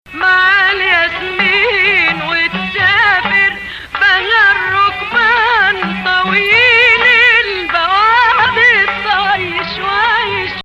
Hijaz 5
melodic inclusion of 4 under tonicized 5